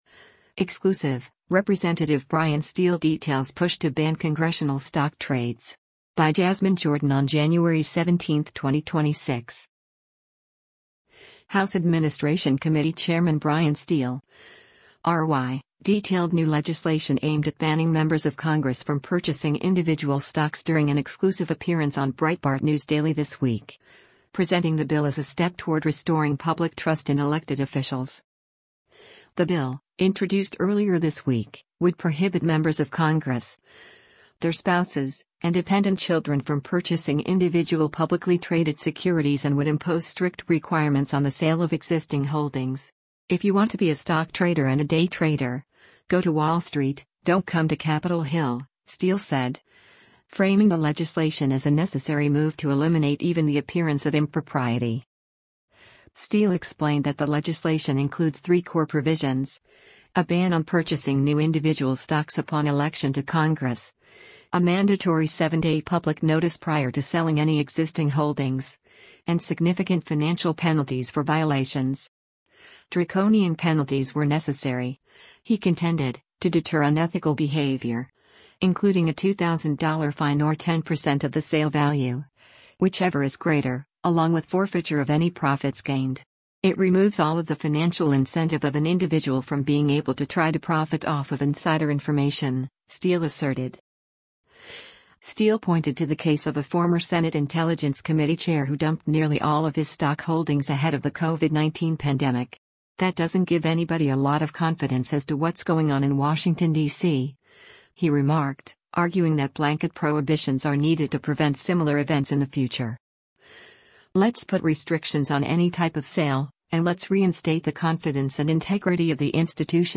House Administration Committee Chairman Bryan Steil (R-WI) detailed new legislation aimed at banning members of Congress from purchasing individual stocks during an exclusive appearance on Breitbart News Daily this week, presenting the bill as a step toward restoring public trust in elected officials.